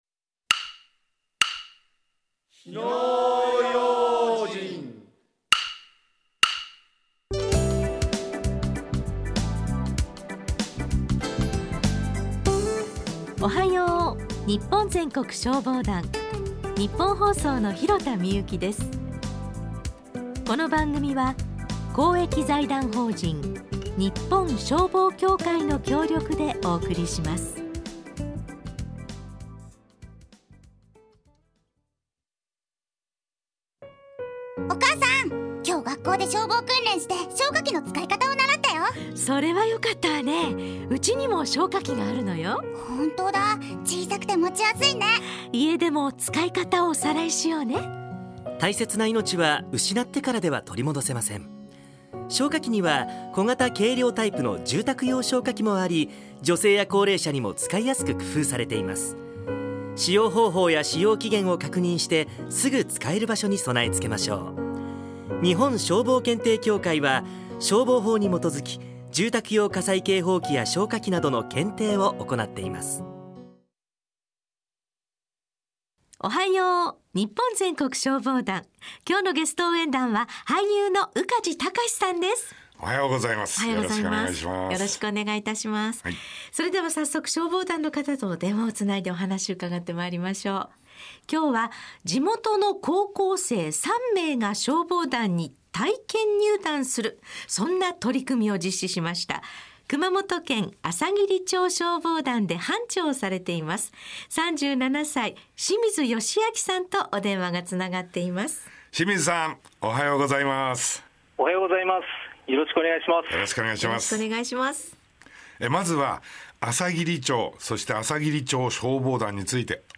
ラジオ放送
「おはよう！ニッポン全国消防団」のラジオ出演の依頼を受けた時は、上手くできるのか不安はありましたが、打ち合わせをしていくうちに、次第に緊張が解け、俳優の宇梶剛士さんとスムーズに受け答えができたと思います。